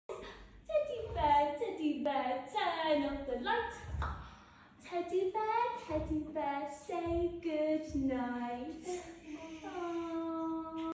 bedtime song? 🧸✨ We get the fuzzy feelings too - but “Teddy Bear, Teddy Bear” isn’t just cute… it’s clever too!
playful changes in volume
All wrapped up in a gentle, joyful melody that little ones love.